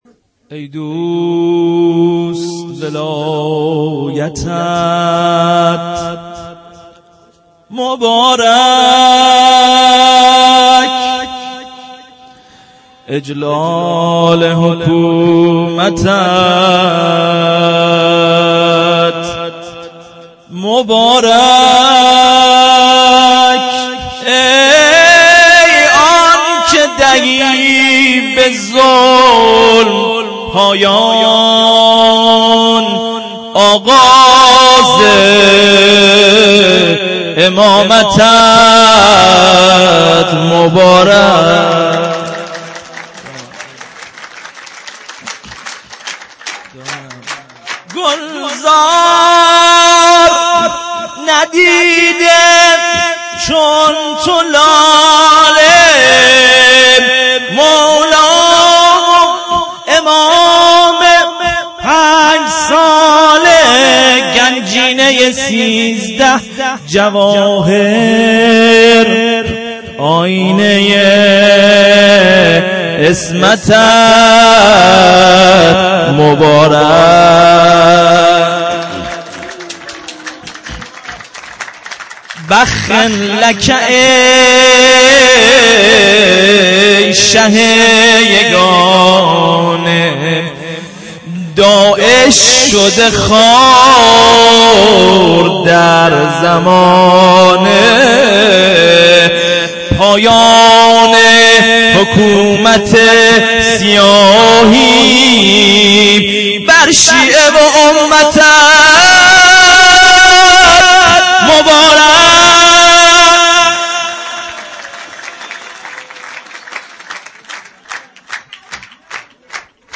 مدح خوانی مدح 2 MB 6:50